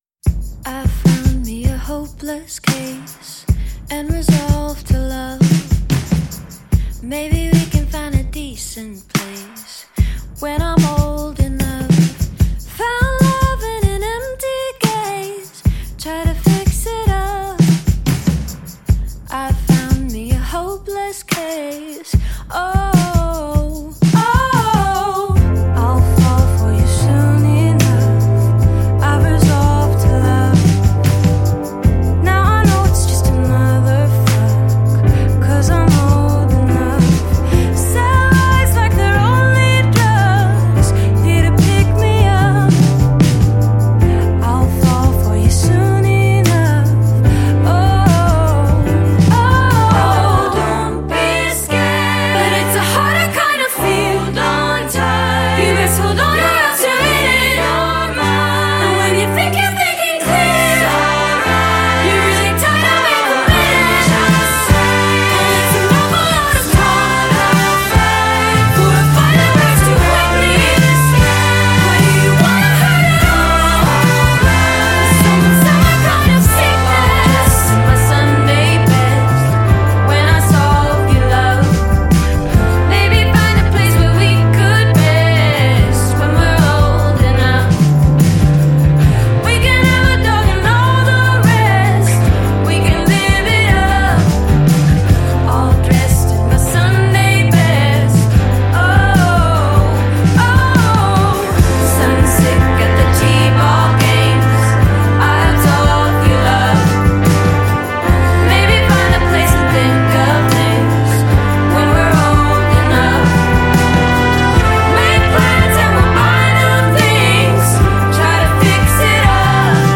indie-rock sound with classical flourishes